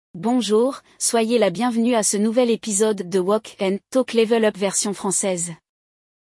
No episódio de hoje, vamos ouvir uma conversa entre duas amigas!